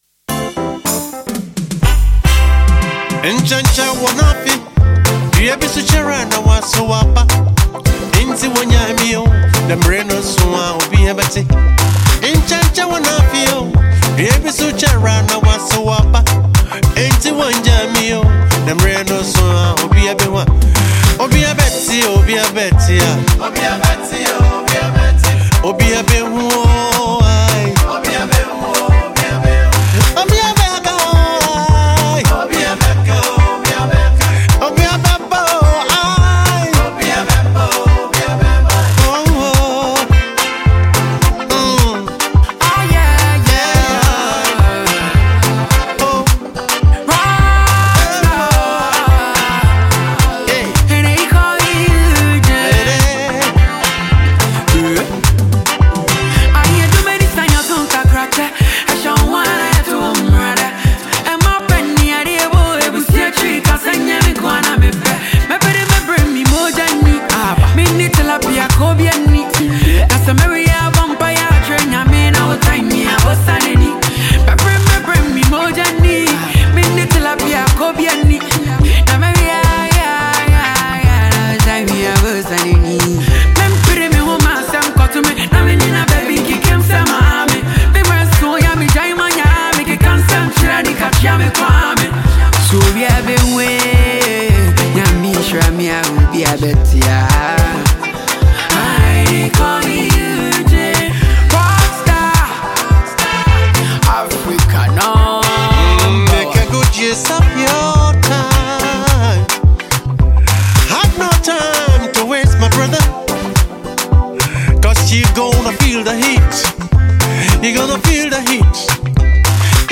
highlife song